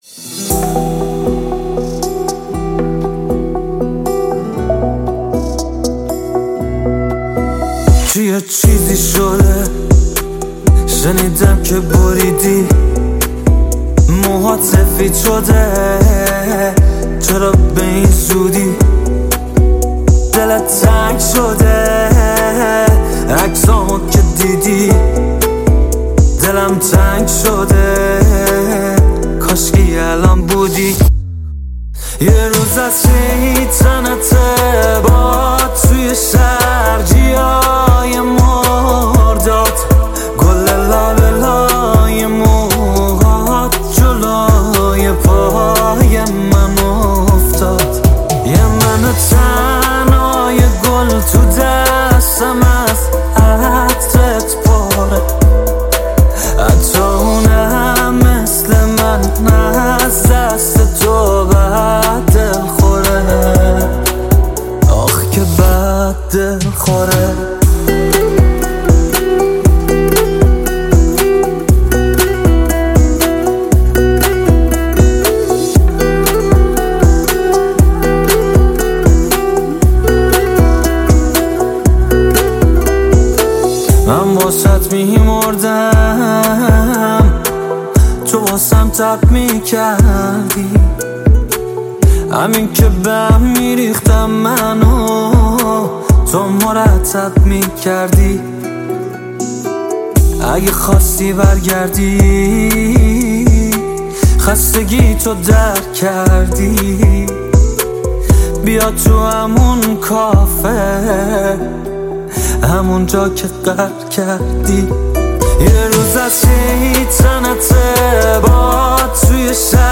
ریمیکس فعلا منتشر نشده